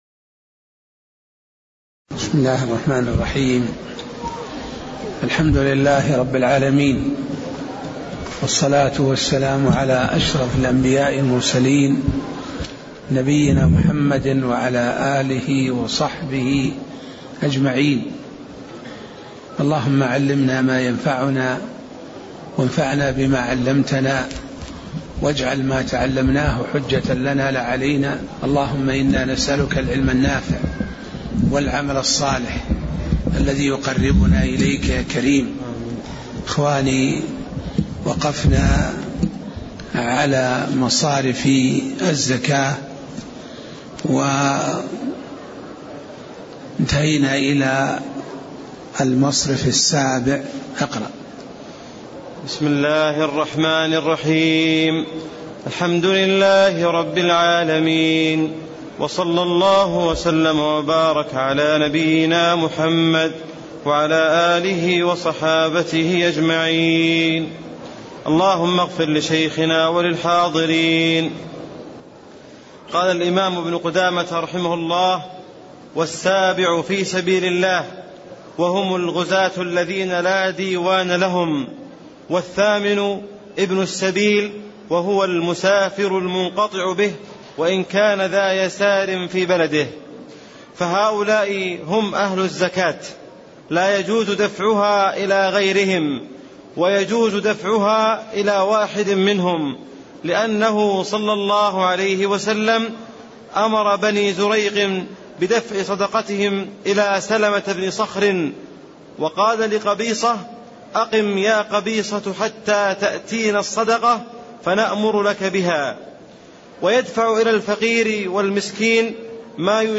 تاريخ النشر ١٠ شعبان ١٤٣٤ هـ المكان: المسجد النبوي الشيخ